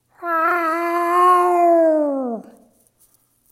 meows-4.mp3